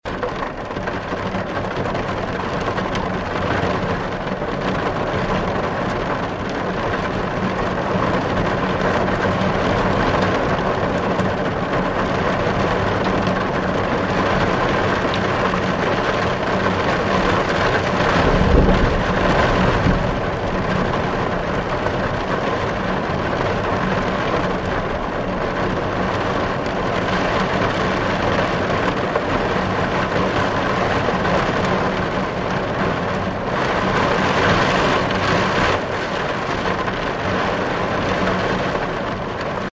Шум дождя и стук капель по стеклу в машине